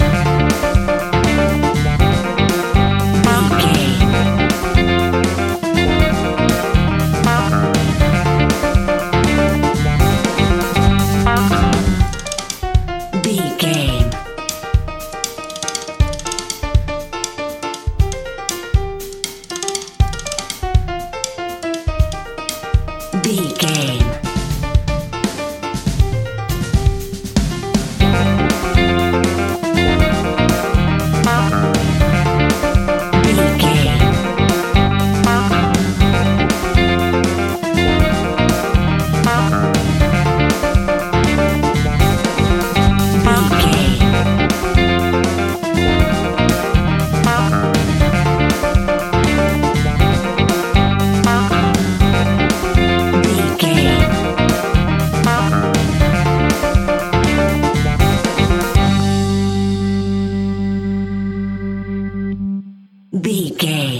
Aeolian/Minor
latin
uptempo
bass guitar
percussion
brass
saxophone
trumpet
fender rhodes